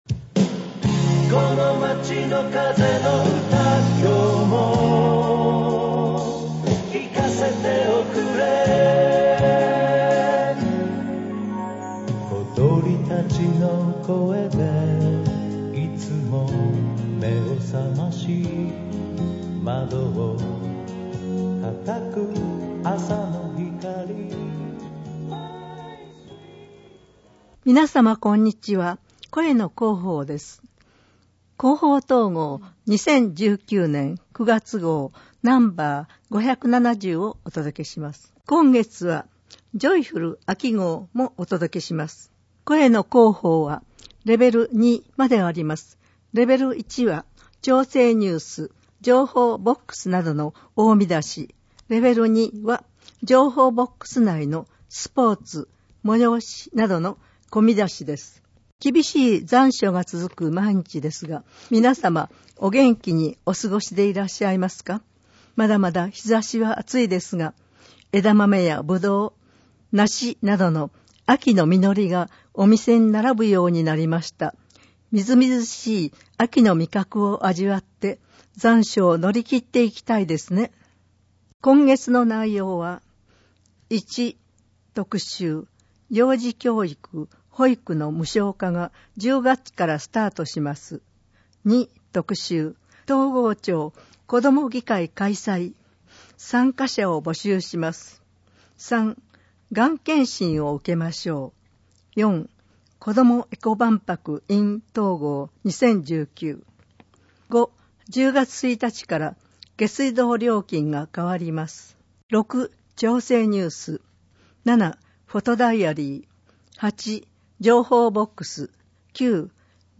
広報とうごう音訳版（2019年9月号）